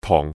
Yue-tong4.mp3